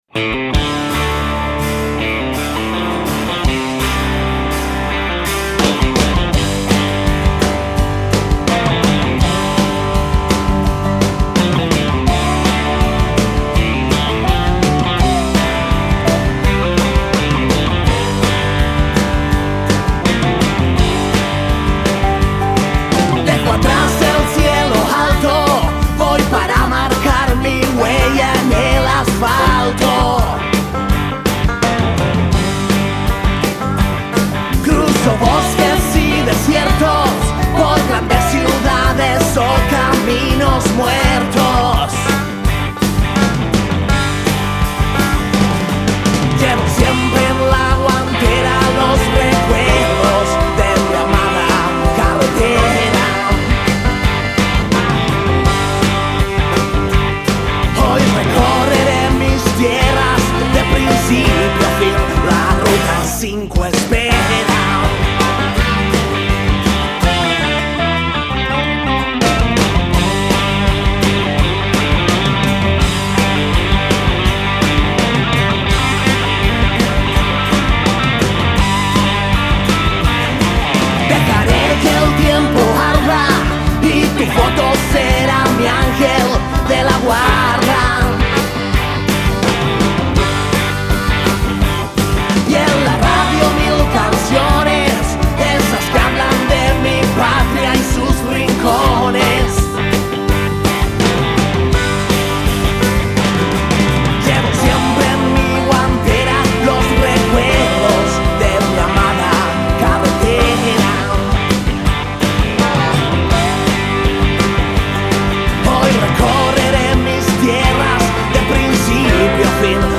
siempre con una músiquita country de fondo
el cuál fue hecho en Santuario Sonico de Santiago hace un mes atrás